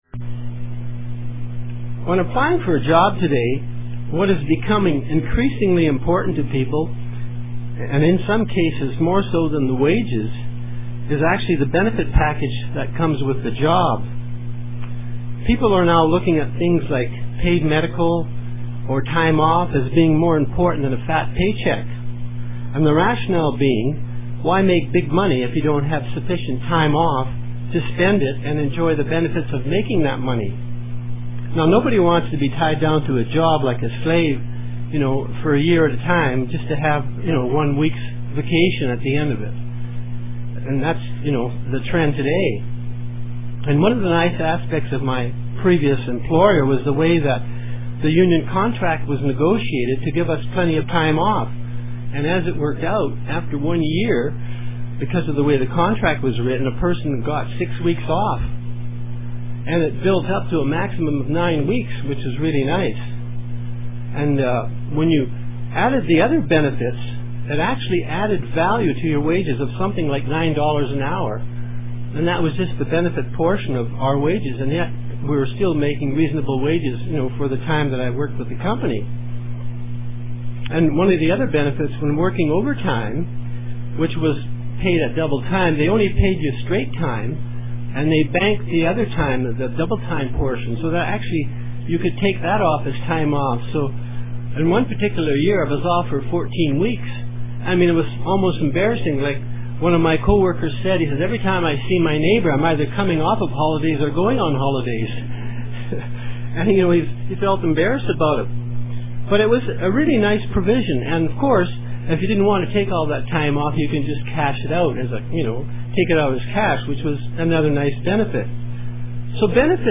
Sermons – Page 185 – Church of the Eternal God